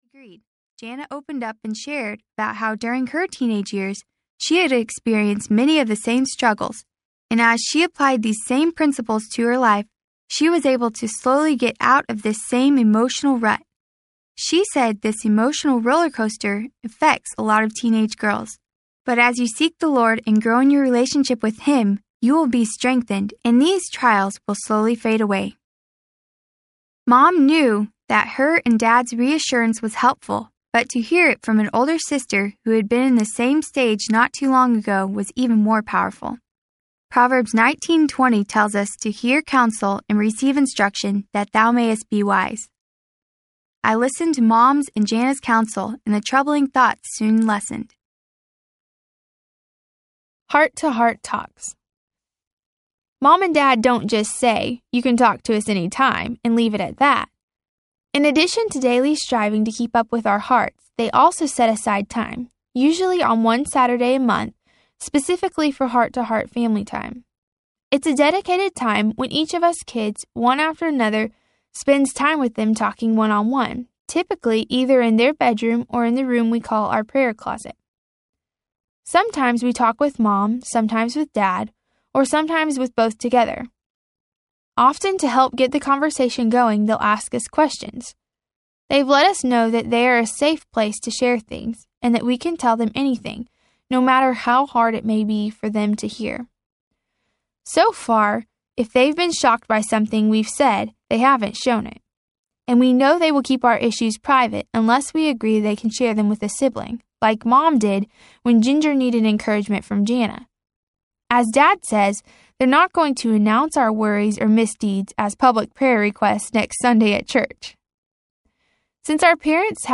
Growing Up Duggar Audiobook
6.6 Hrs. – Unabridged